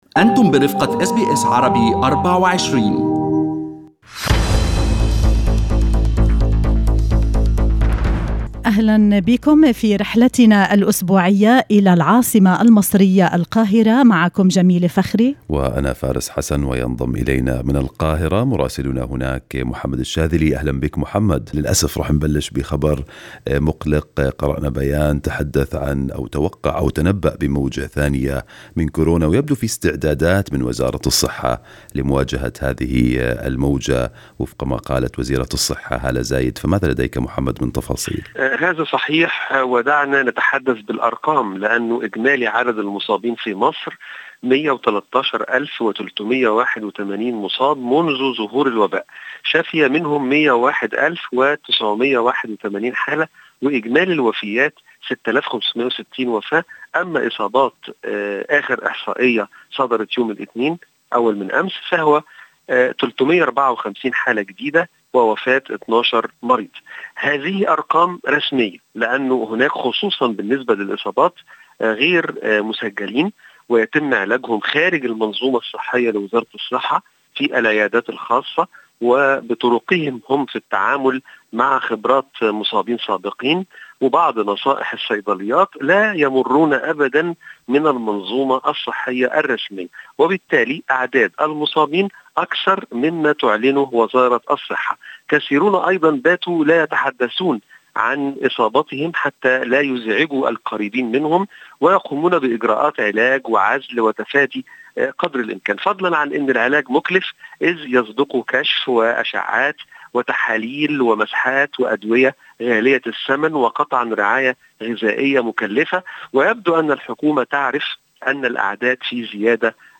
يمكنكم الاستماع إلى تقرير مراسلنا في القاهرة بالضغط على التسجيل الصوتي أعلاه.